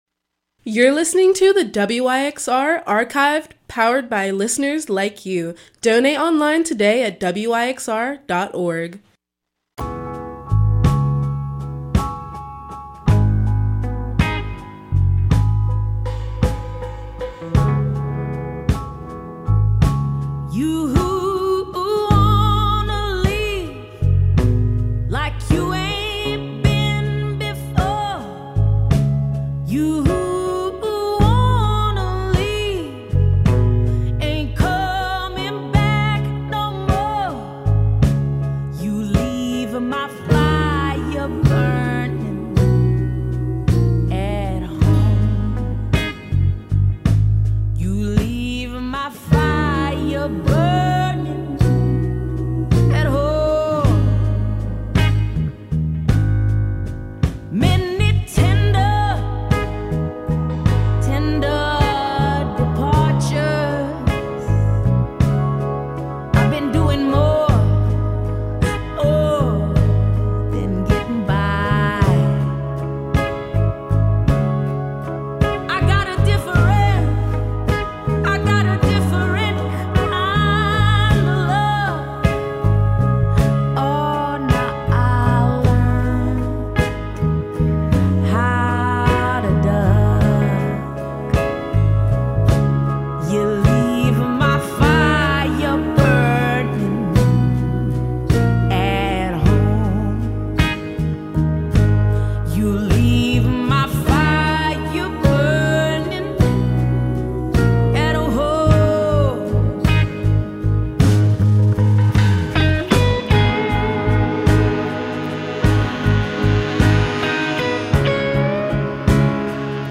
No genre rules, just all Memphis-made music, all the time. Plus interviews, guest curators, and more.